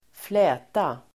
Uttal: [²fl'ä:ta]